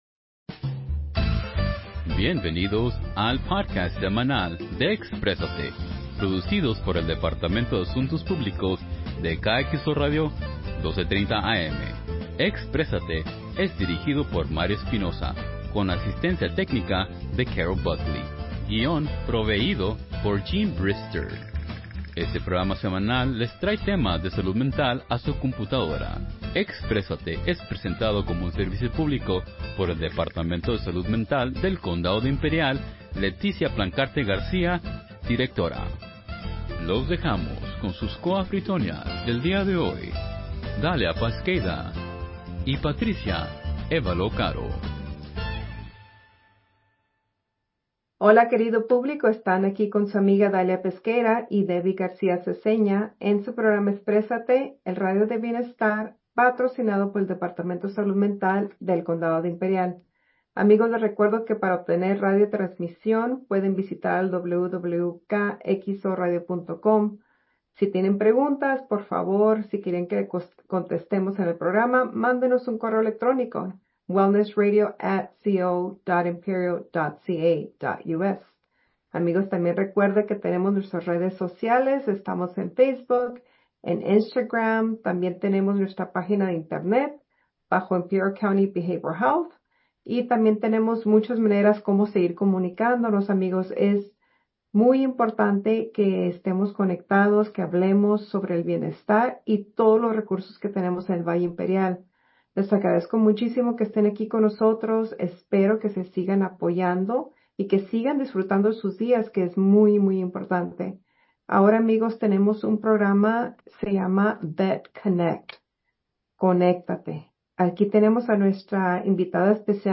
Talk Radio/Behavioral health